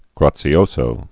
(grätsē-ōsō, -zō)